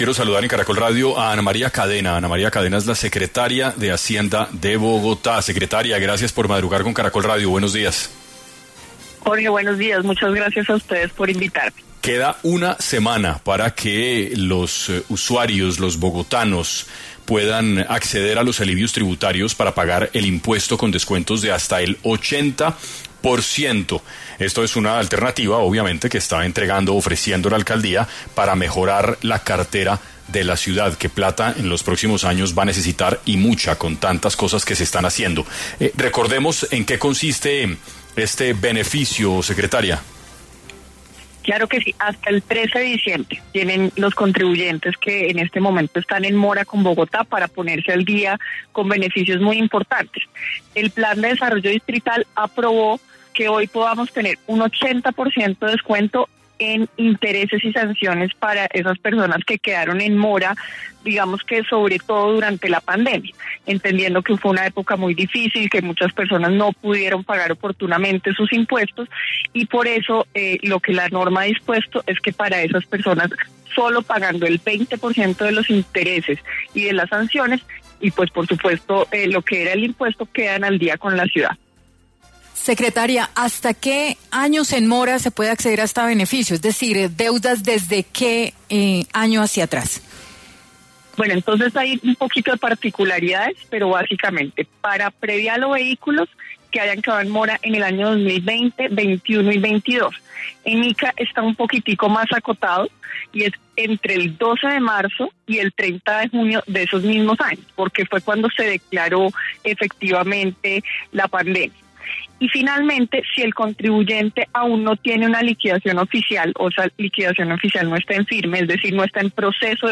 En 6AM de Caracol Radio estuvo Ana María Cadena, secretaria de Hacienda de Bogotá, para hablar sobre hasta cuándo y cómo se puede acceder a los alivios tributarios para pagar impuestos con descuentos de hasta el 80%.